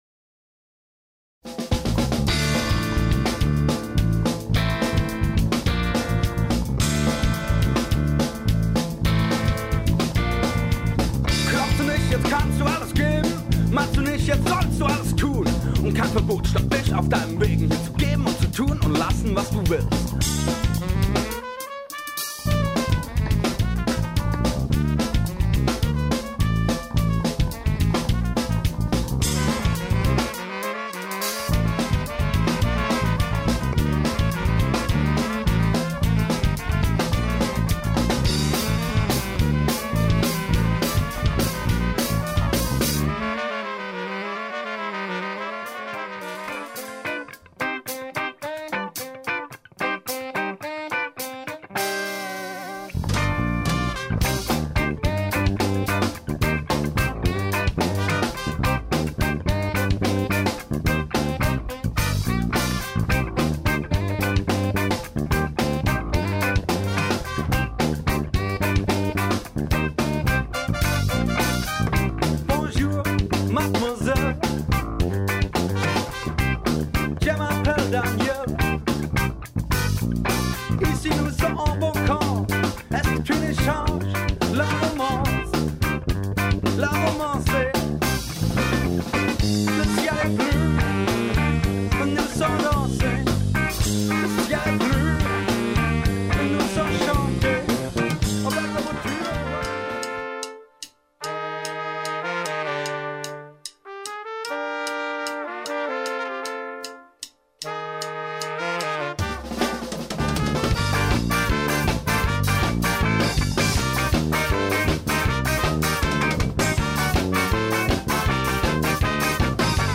Medley Mix - Hörprobe